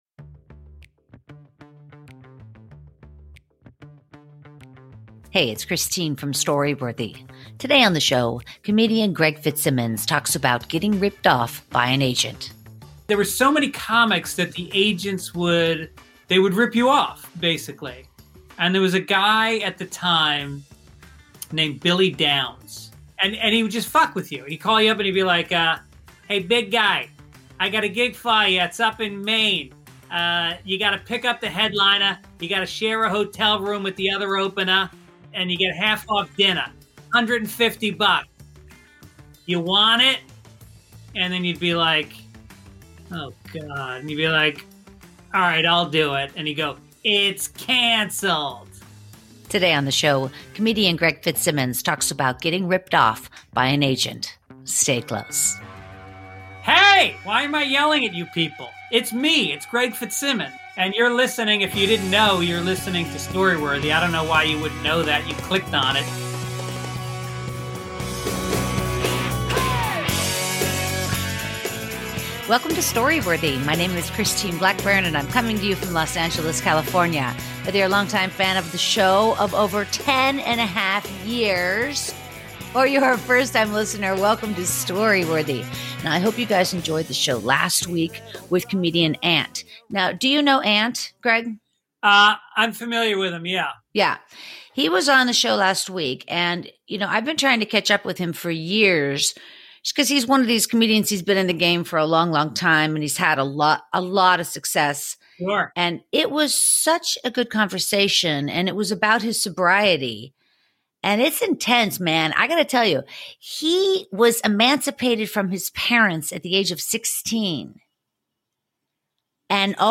662 - Getting Ripped Off By an Agent with Comedian Greg Fitzsimmons